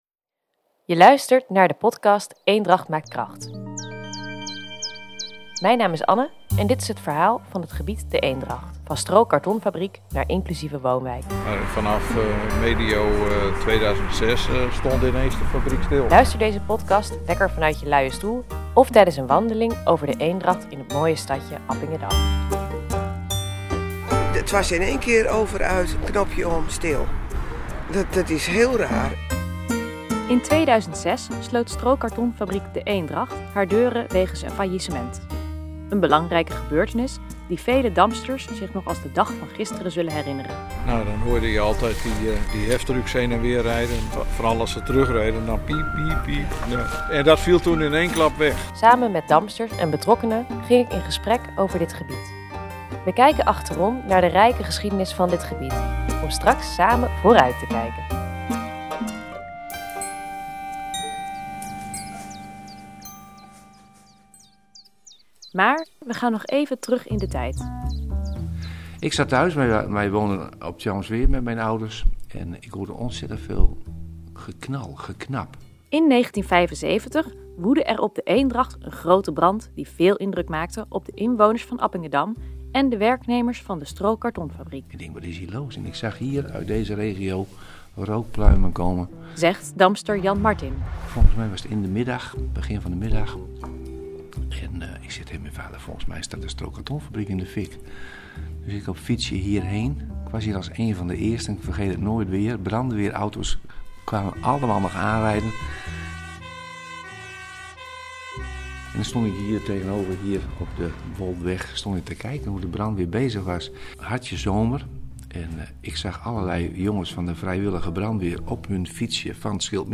In de podcast komen oud-werknemers, buren en andere “Damsters” aan het woord.